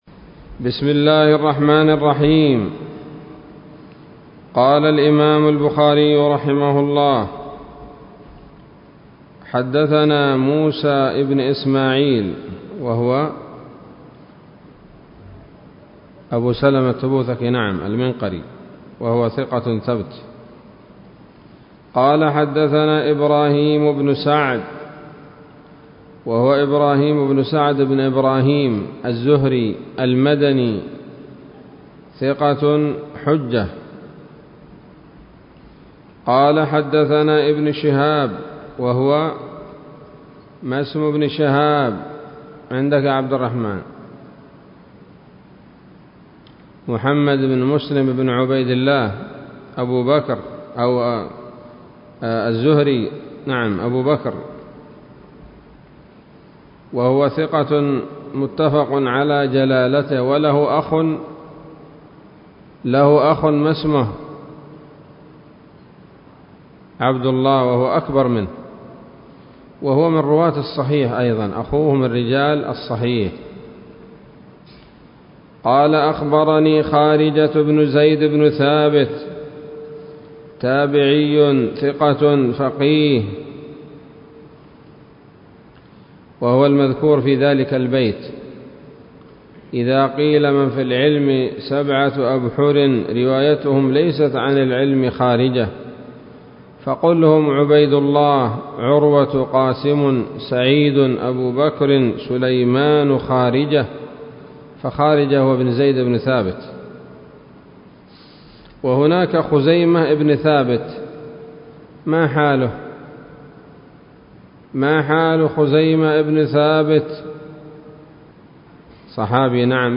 الدرس الثالث والثلاثون من كتاب المغازي من صحيح الإمام البخاري